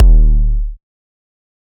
EDM Kick 27.wav